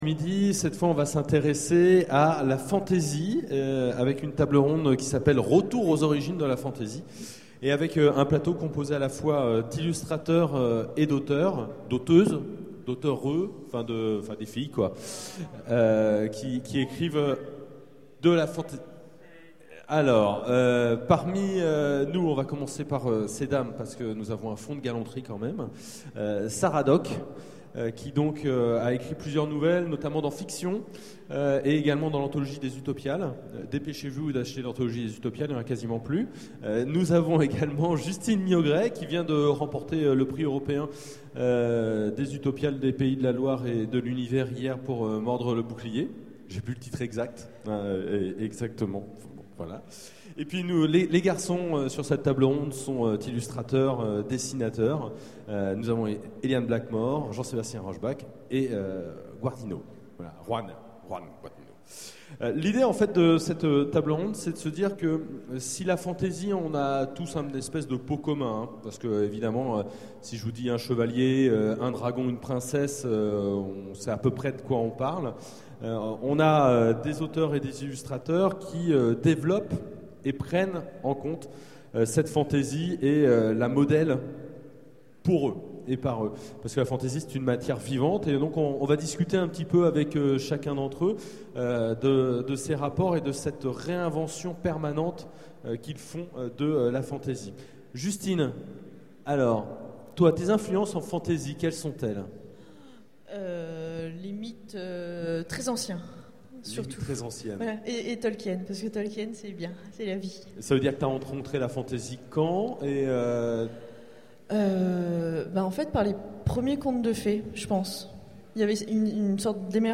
Utopiales 12 : Conférence Retour aux origines de la fantasy